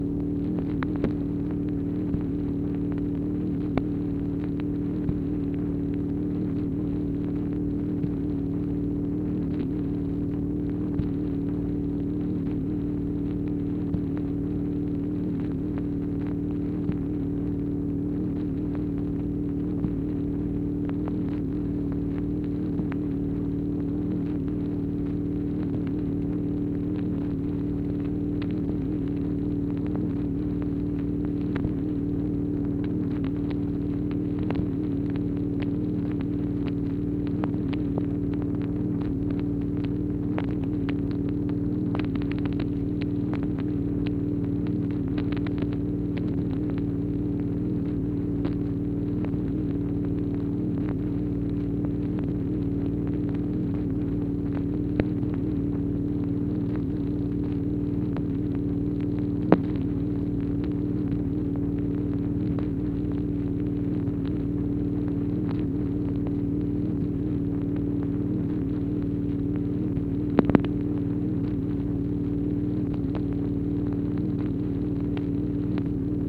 MACHINE NOISE, January 8, 1964
Secret White House Tapes | Lyndon B. Johnson Presidency